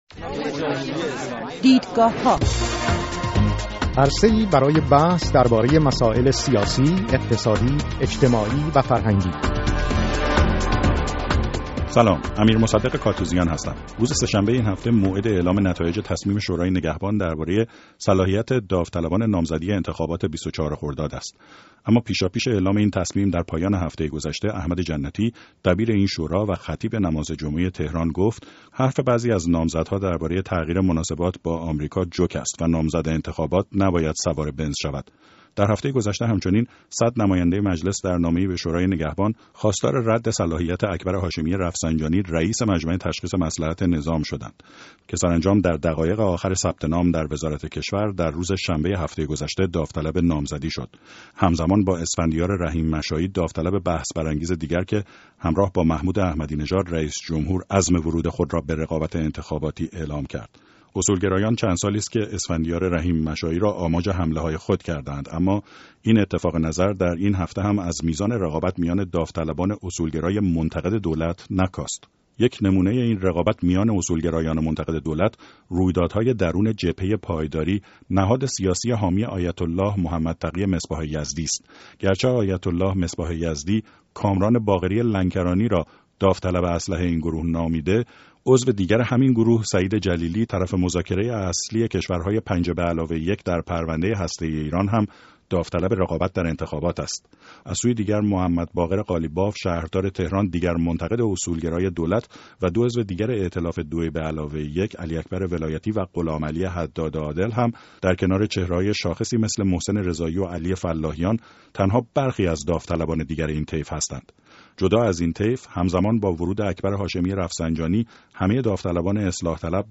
سه میهمان برنامه این هفته دیدگاه‌هاچشم‌انداز تصمیم روز سه‌شنبه شورای نگهبان و مساله صیانت آرا در انتخابات ۲۴ خرداد را بررسی می‌ کنند.